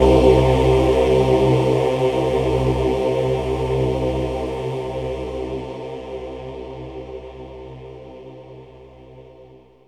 VOX_CHORAL_0007.wav